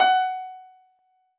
piano-ff-58.wav